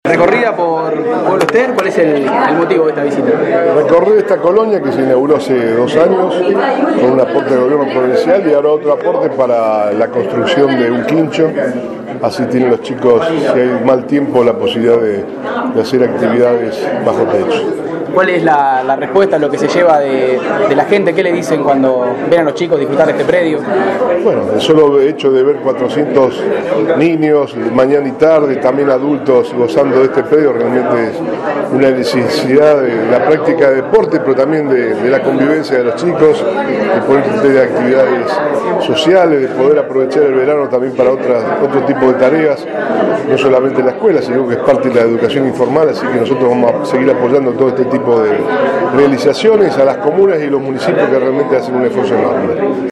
El gobernador Antonio Bonfatti recorrió la colonia de vacaciones que funciona en el Polideportivo Comunal de Pueblo Esther y entregó un aporte no reintegrable de 135 mil pesos para sumar nuevos espacios.
Declaraciones de Bonfatti.